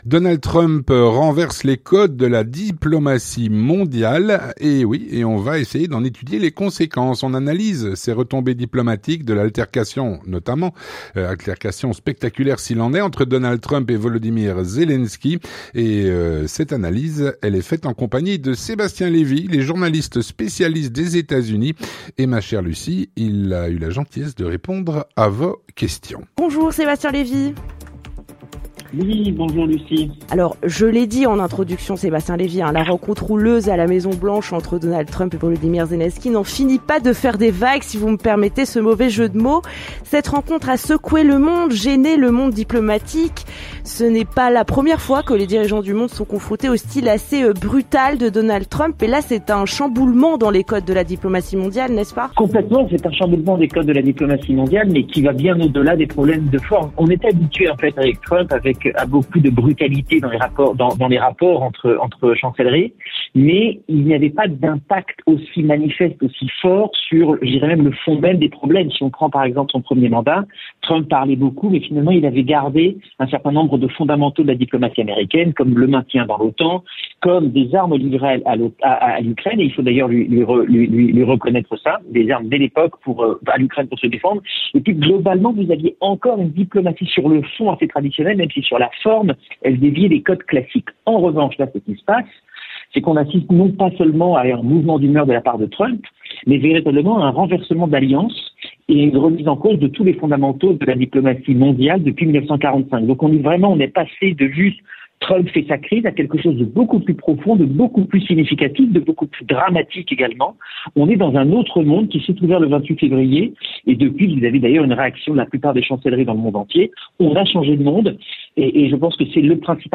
L'entretien du 18H - Donald Trump renverse les codes de la diplomatie mondiale.